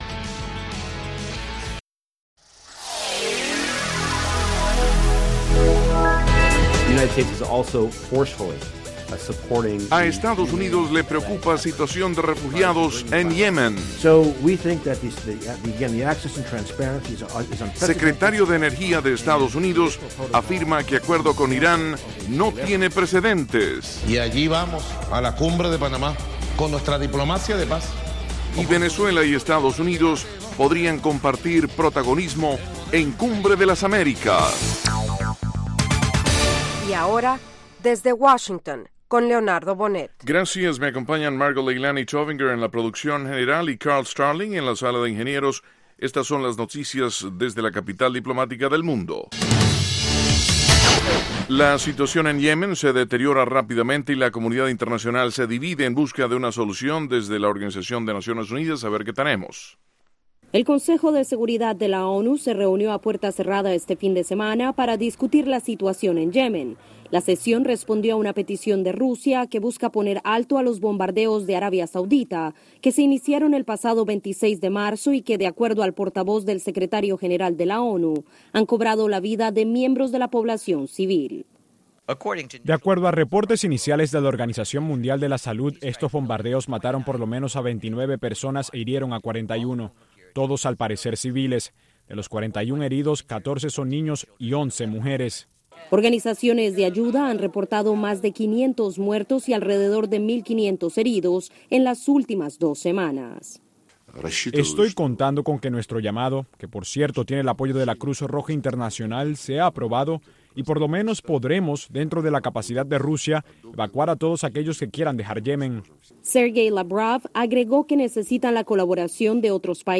Diez minutos de las noticias más relevantes del día, ocurridas en Estados Unidos y el resto del mundo.